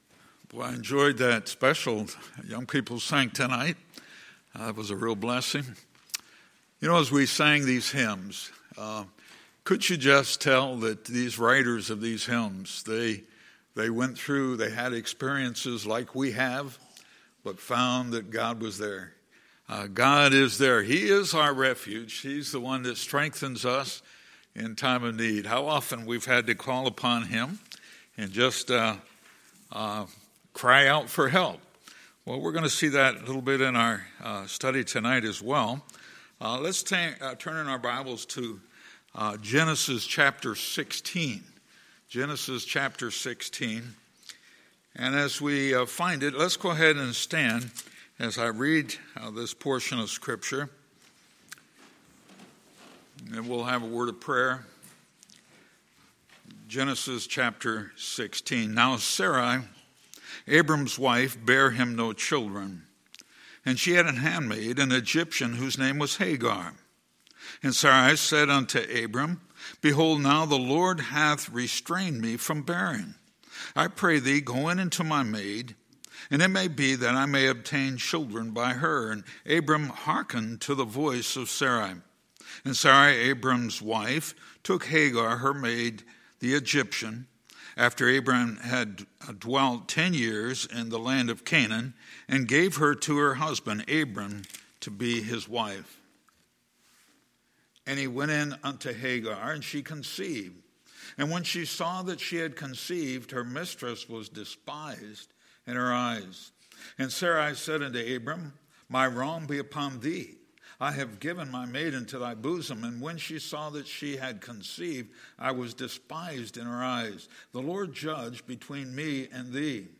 Sunday, November 10, 2019 – Sunday Evening Service